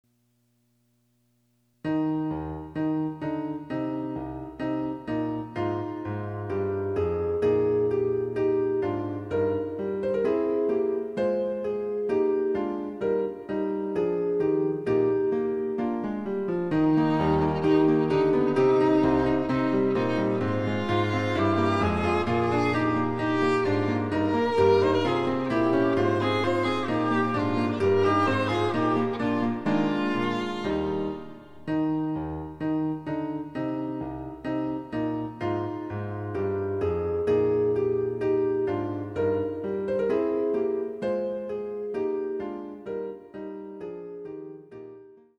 ★ヴァイオリン用の名曲をピアノ伴奏つきで演奏できる、「ピアノ伴奏ＣＤつき楽譜」です。